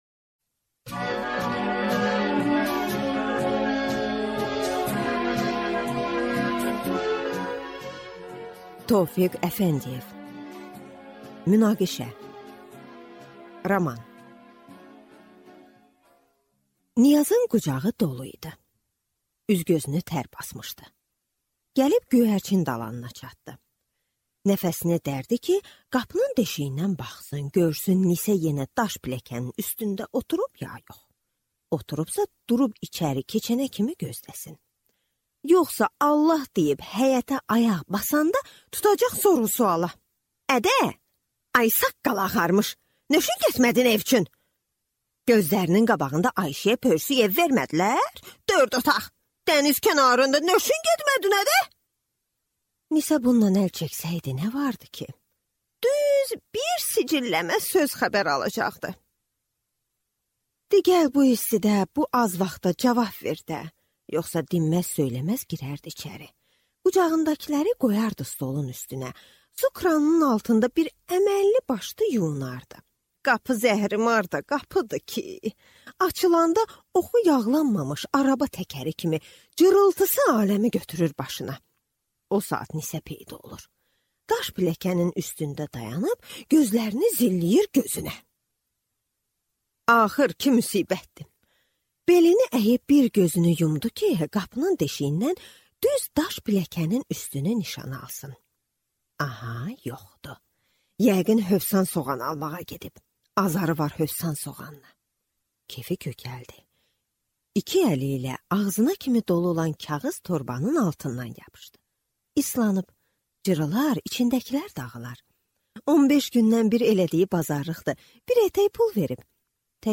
Аудиокнига Münaqişə | Библиотека аудиокниг